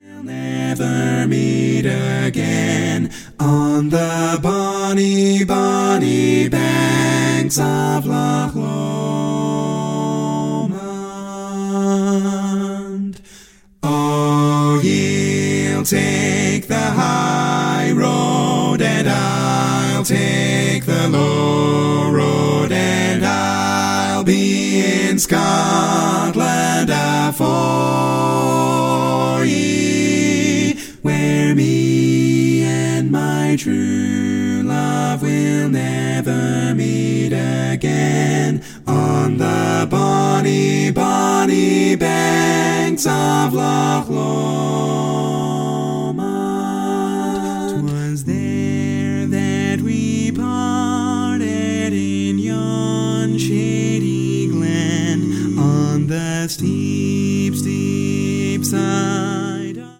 Category: Male